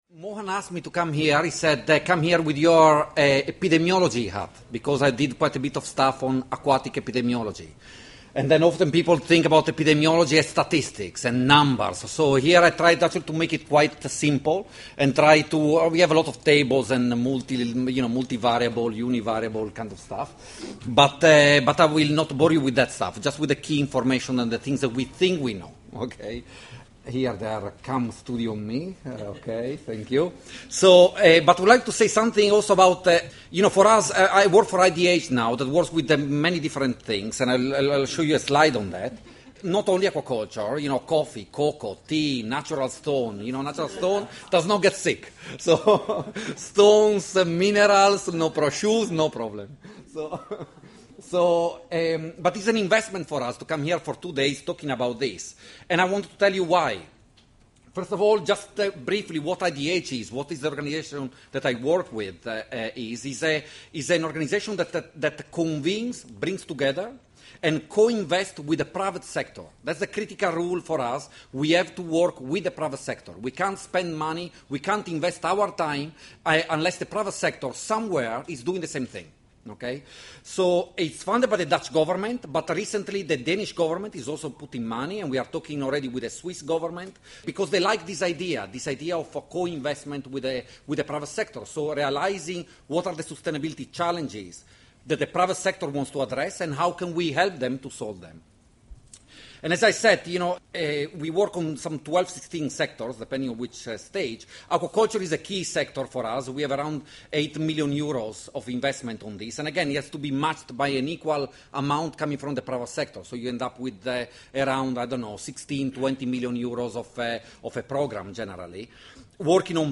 Presentation on the epidemiology and risk factors for acute hepatopancreatic necrosis syndrome.